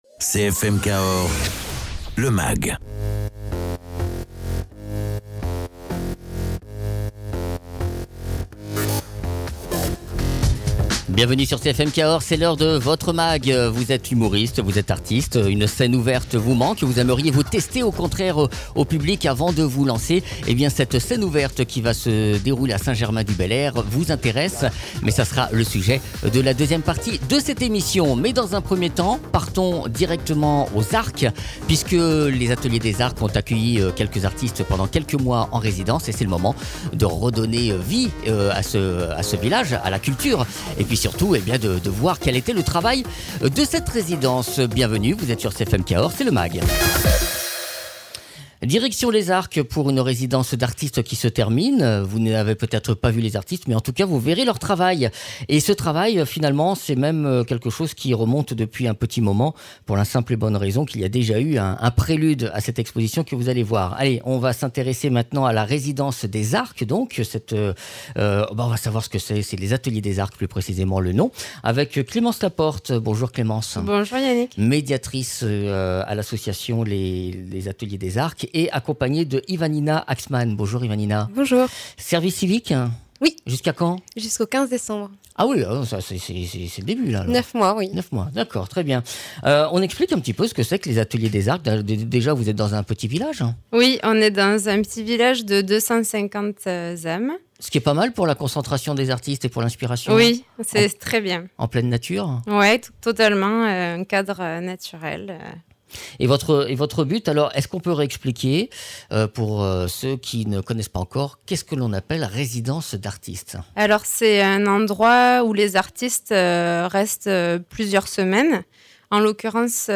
médiatrice culturel.
Humoriste.